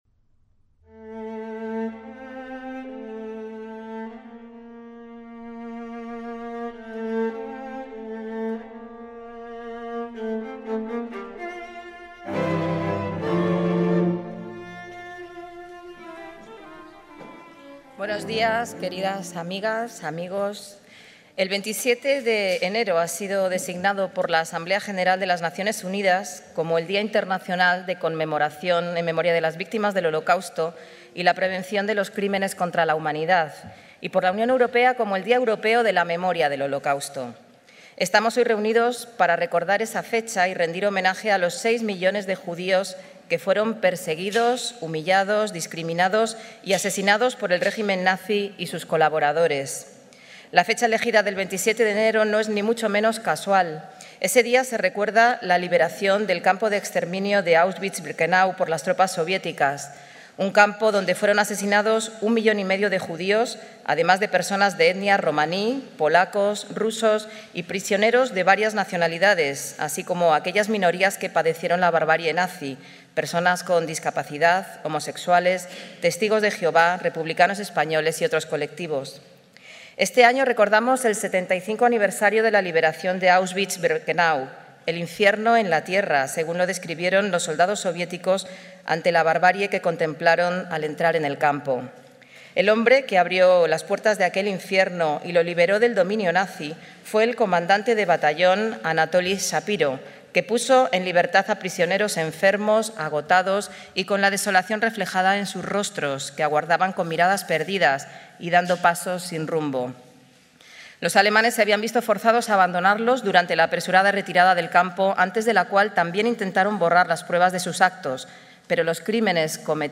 ACTOS EN DIRECTO - El Ayuntamiento de Madrid se ha sumado el 4 de febrero de 2020 a los actos de homenaje en conmemoración del 75 aniversario de la liberación del campo de exterminio de Auschwitz-Birkenau por las tropas soviéticas, encuentro en el que, desde la Plaza de la Villa, se ha llamado a no olvidar este "infierno en la tierra" que acabó con la vida de seis millones de judíos.